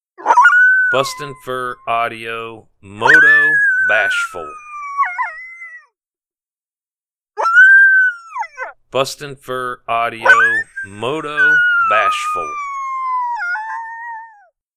Moto Bashful is a series of juvenile howls done by BFA’s popular Coyote MotoMoto! Excellent howl to mix in on any vocal stand.